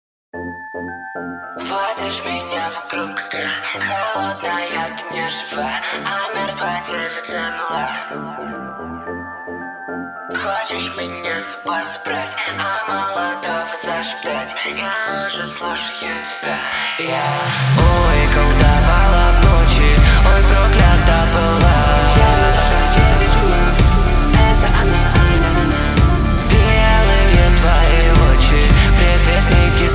Жанр: Русская поп-музыка / Поп / Рок / Русский рок / Русские